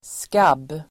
Ladda ner uttalet
skabb.mp3